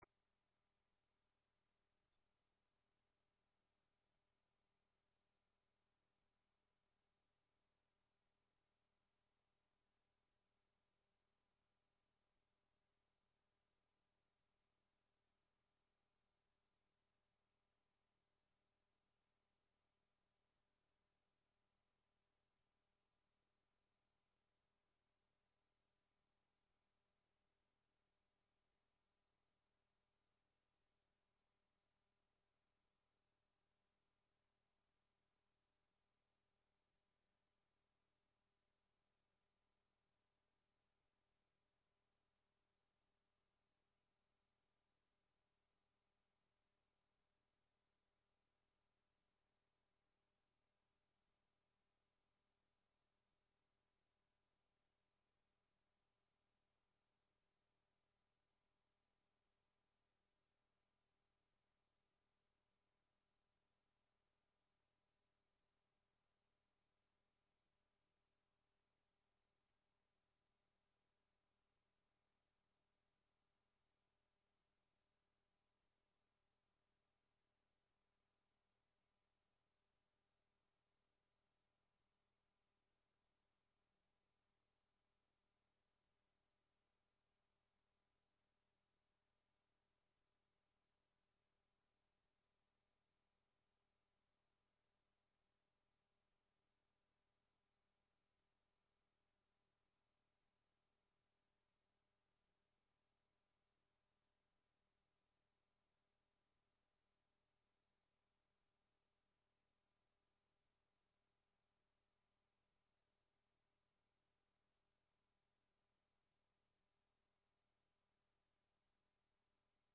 Audio de la presentación